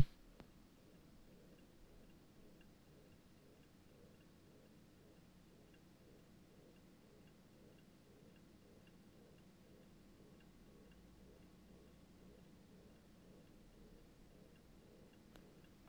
Ein rhythmisches Schnarren meines NT be quiet! Straight Power 11 750W, was immer wieder an- und abschwellt. Begleitet von einem leichten Klacken.
Man muss aber schon laut aufdrehen, um es zu hören. Aber dann kann man es IMHO schon durch das Hintergrundrauschen der anderen Lüfter hindurch wahrnehmen.